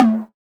Toomp KB (perc).wav